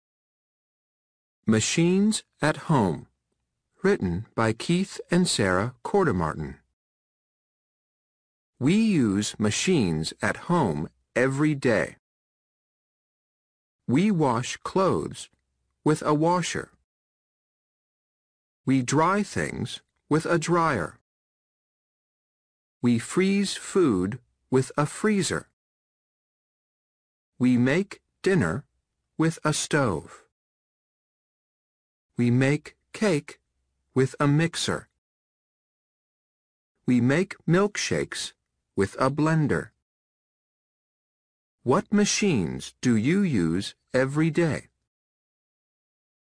听力练习：